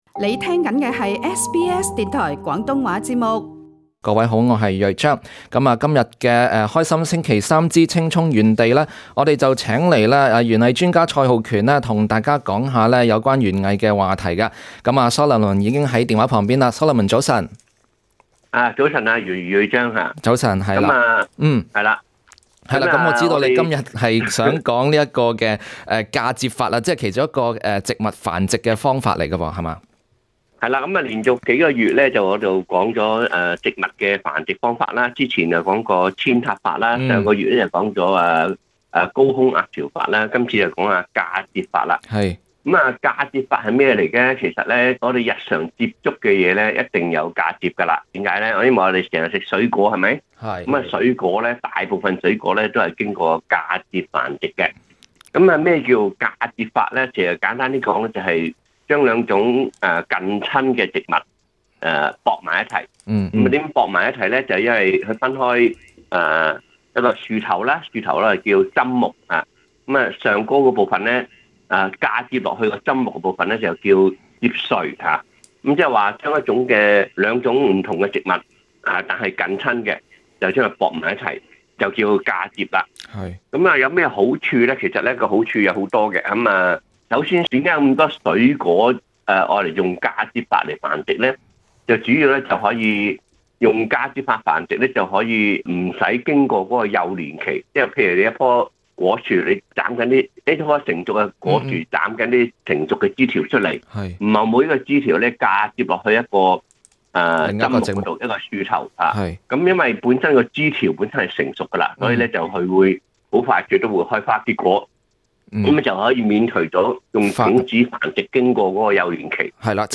同時，節目中亦接聽聽眾電話並解答大家的園藝問題。